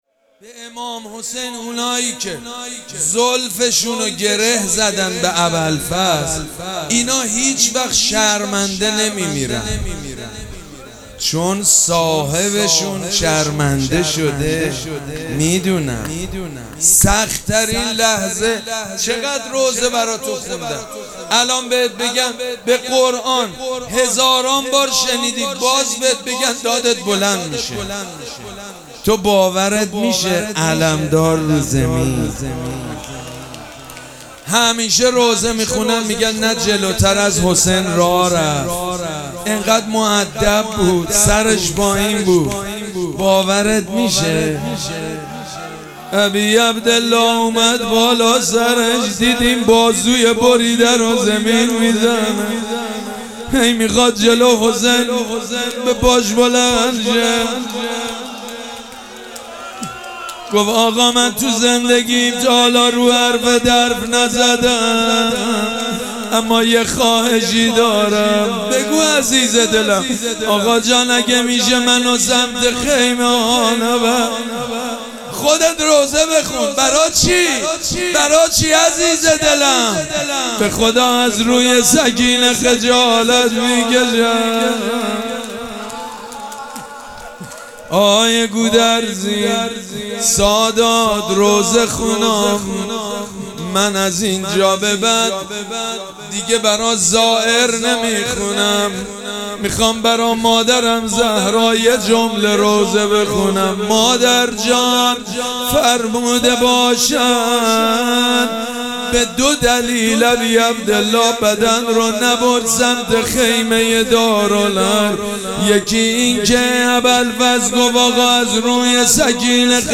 شب چهارم مراسم عزاداری اربعین حسینی ۱۴۴۷
روضه
حاج سید مجید بنی فاطمه